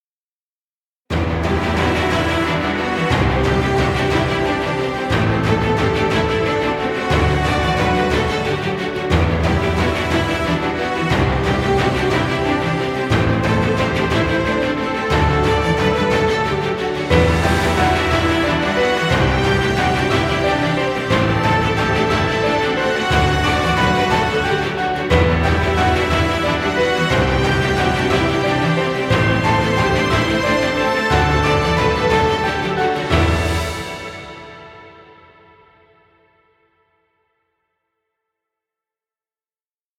Epic inspirational music.